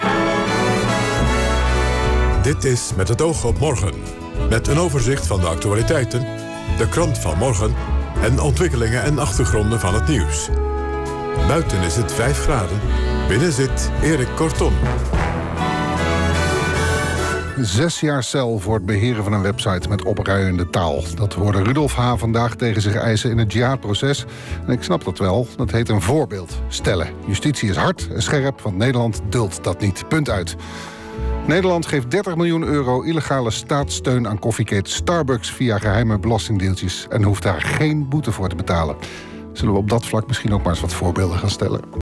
FM-tuner via de kabel, 20 oktober
Bovenstaande drie klinken allemaal uitstekend.